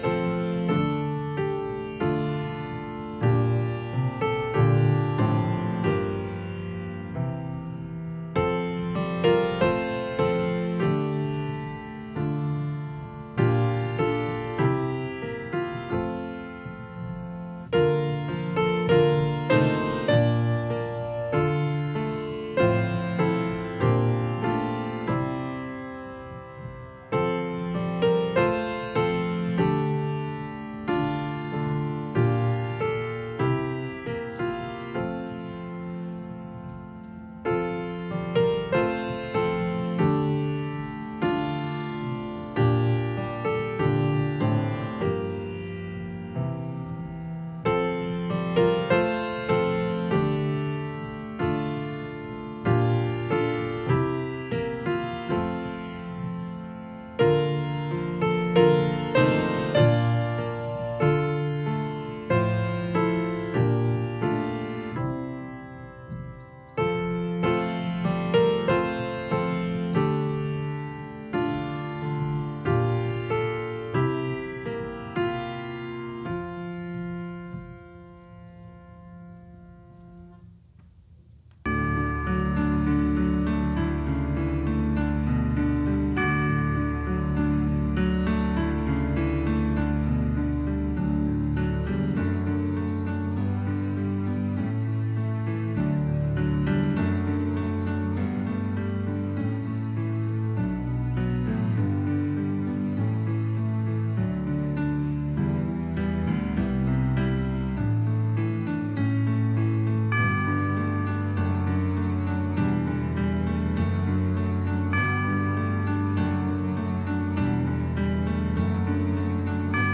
Full worship service: December 12, 2021 (3rd Sunday in Advent)